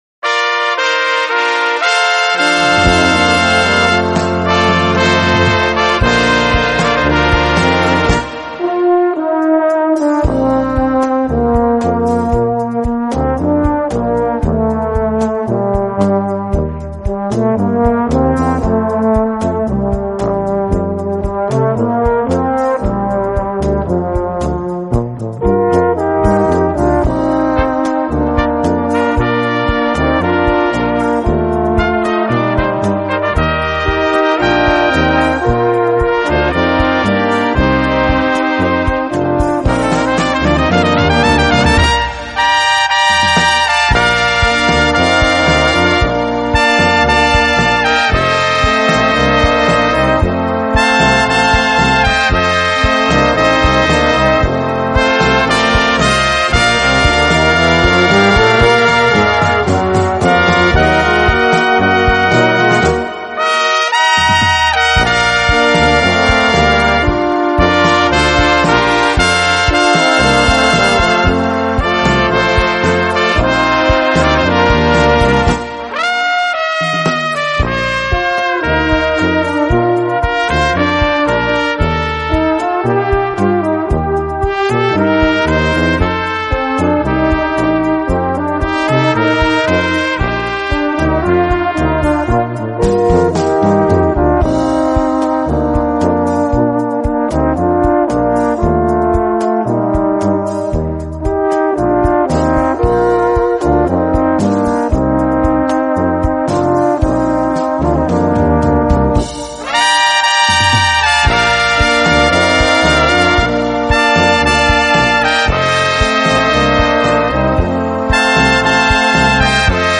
Besetzung: Kleine Blasmusik-Besetzung